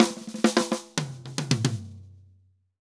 Drum_Break 110_3.wav